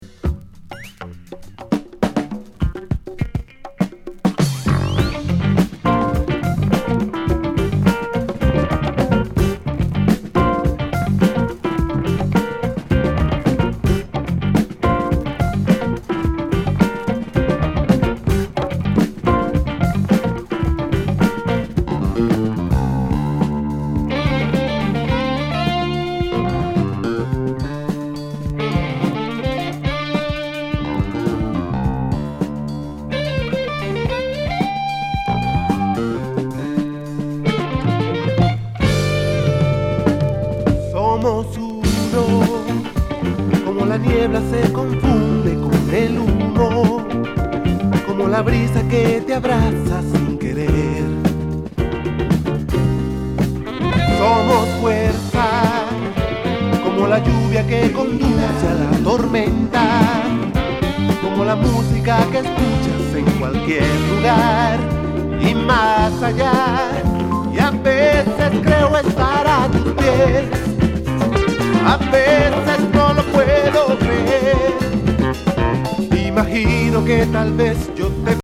グルーヴィAOR